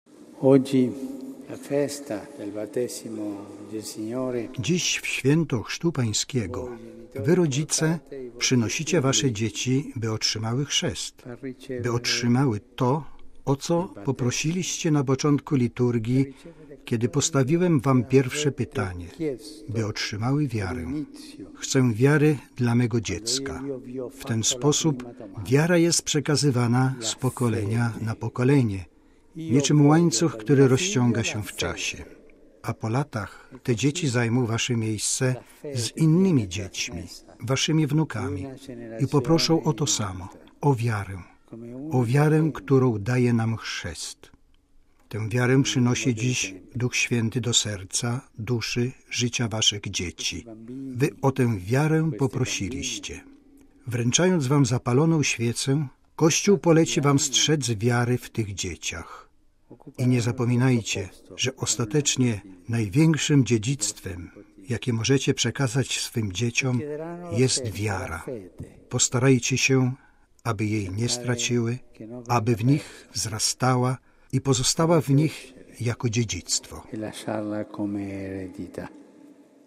Właśnie tam w obliczu Sądu Ostatecznego Michała Anioła Franciszek ochrzcił 26 dzieci, 13 dziewczynek i 13 chłopców.
W wyjątkowo krótkiej homilii Papież powiedział rodzicom, że są oni podobni do Józefa i Maryi, którzy przynieśli małego Jezusa do świątyni, aby ofiarować Go Bogu.